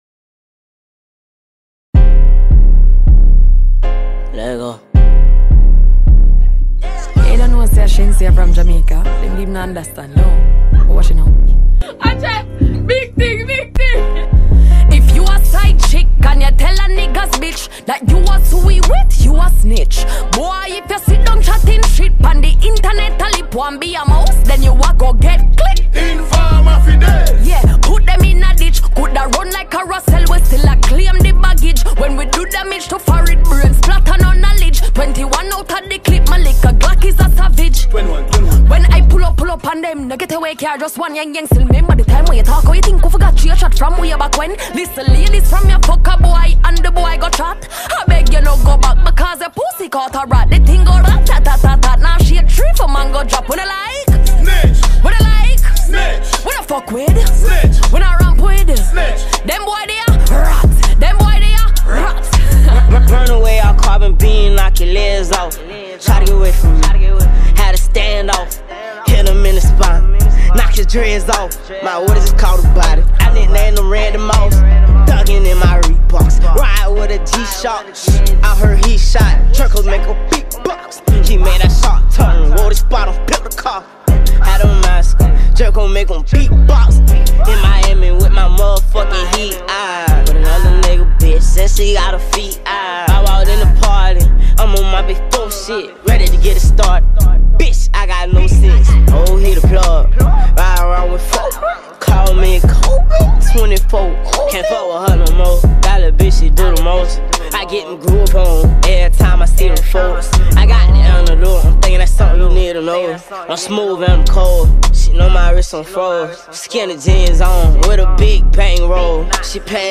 Dancehall Music
This lovely dancehall tune